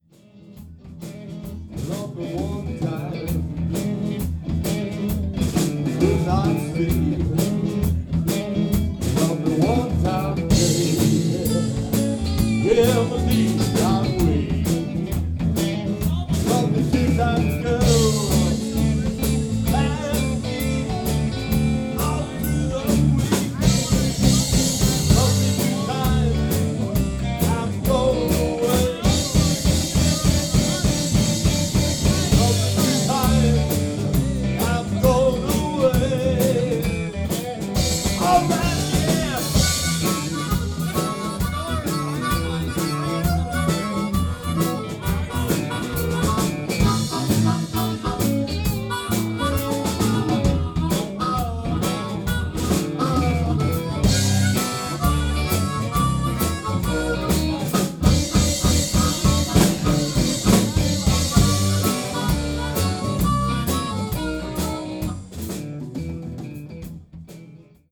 2018 Live Recordings